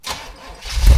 car-engine-start-2.ogg